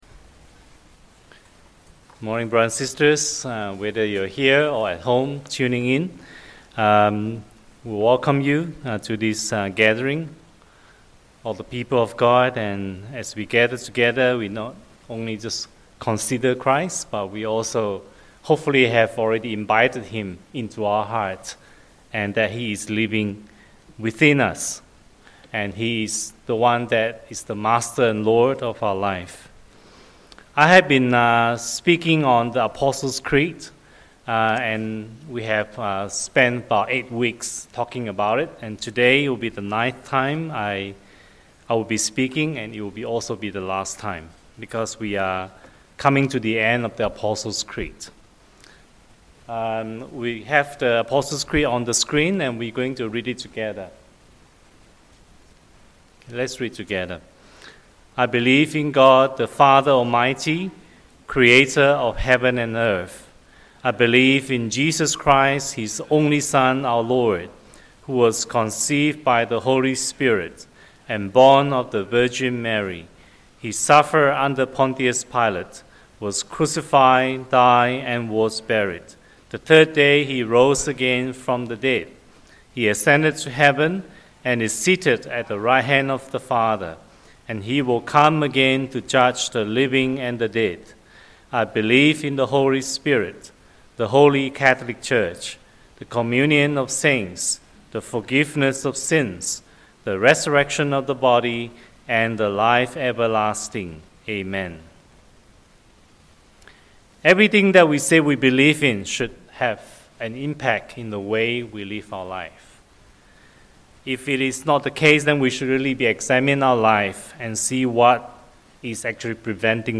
The last talk on Apostles’ Creed, focusing on the resurrection of the body and eternal life, and the implications for our lives.